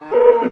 cowhurt3.ogg